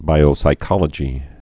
(bīō-sī-kŏlə-jē)